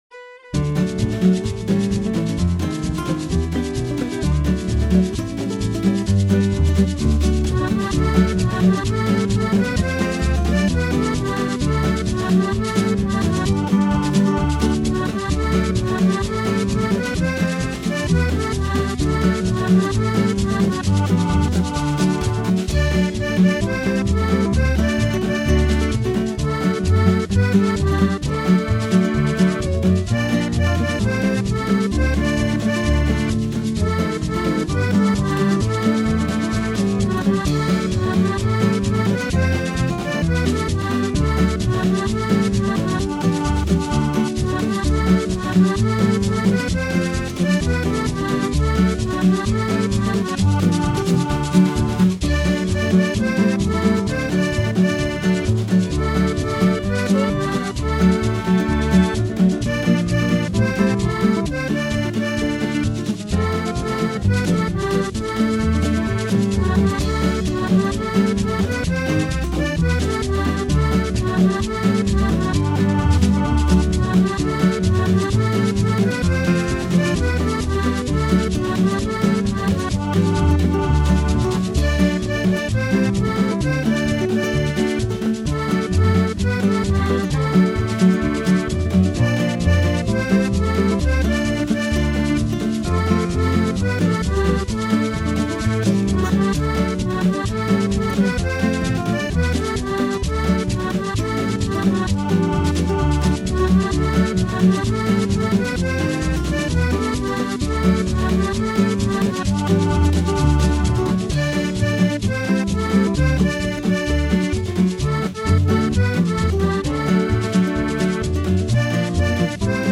My backing tries to shuffle the song along.